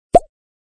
TapUI.mp3